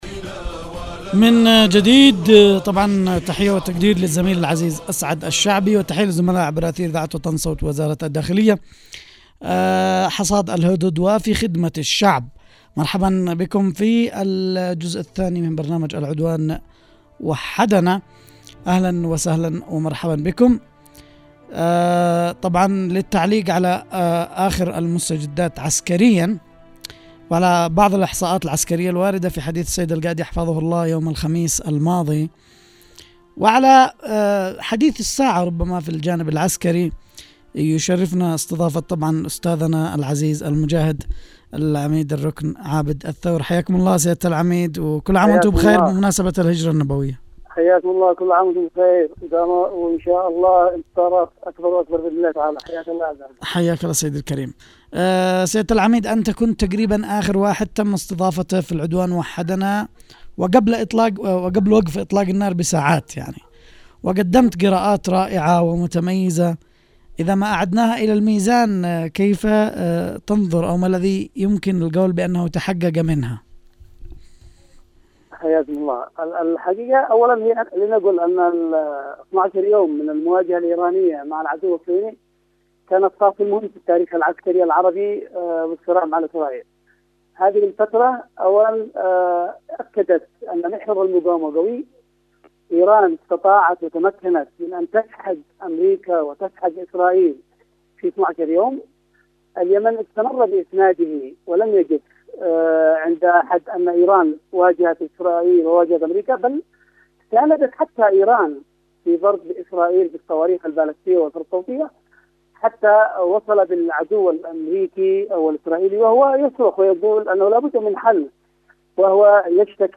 ☎ لقاء عبر الهاتف لبرنامج العدوان وحدنا عبر إذاعة صنعاء البرنامج العام والإذاعات الوطنية المرتبطة مع::